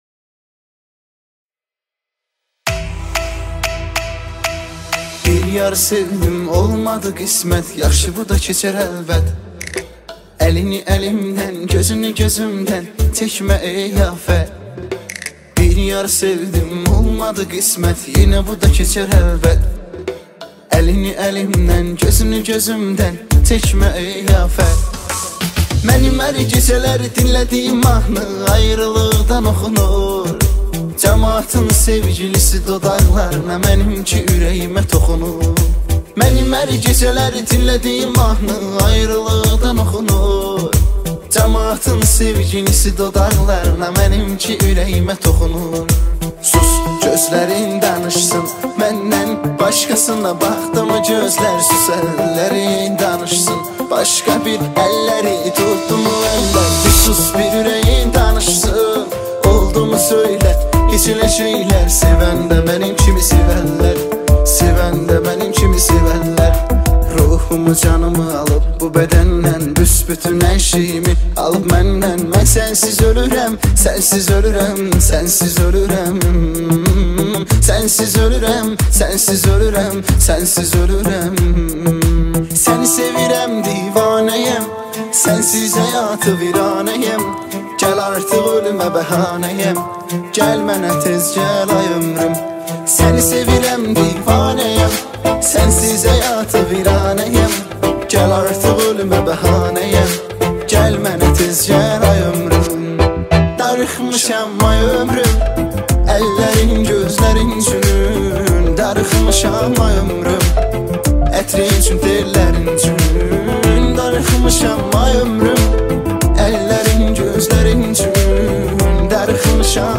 دانلود ریمیکس آذربایجانی
دانلود آهنگ عاشقانه آذری